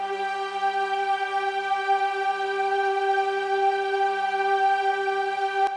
Orchestra
g6.wav